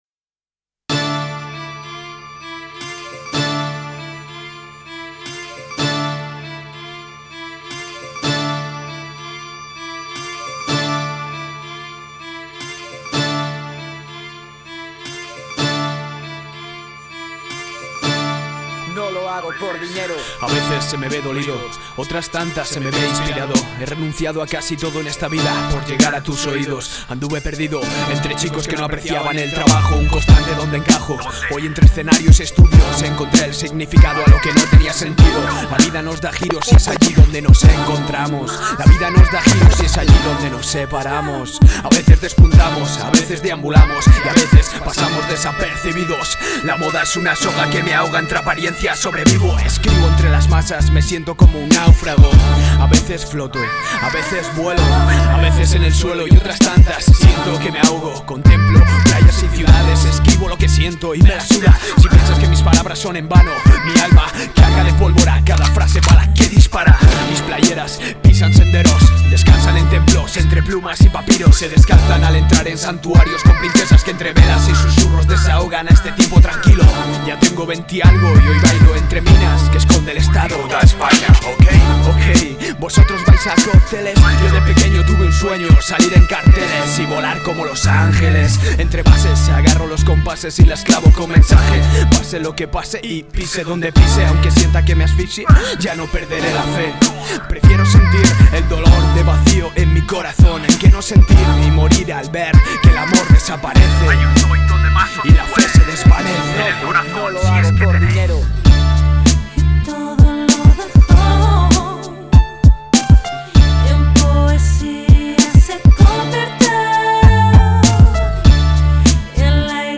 Grabado y mezclado en los estudio